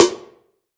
SNARE 104.wav